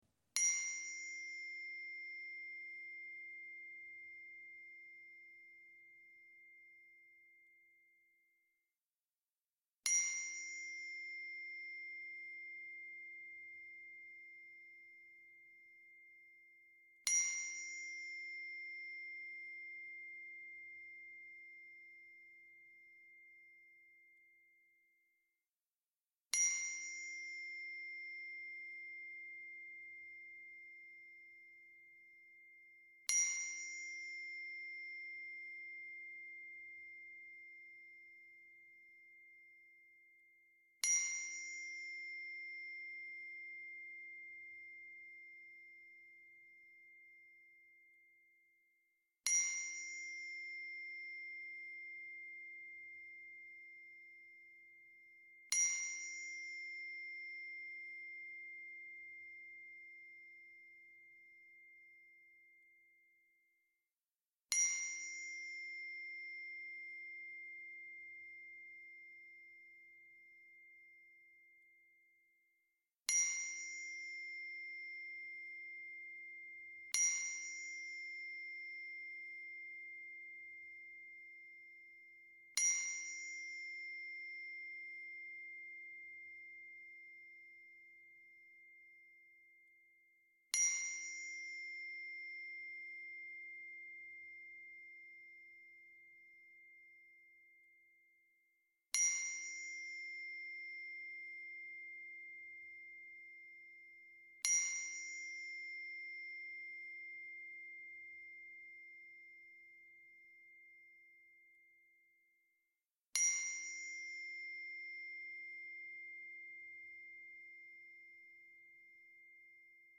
*Bonus* Heart Chakra Tuning Fork Meditation
BONUS-Meditation_Tuning-Fork-Heart-Chakra.mp3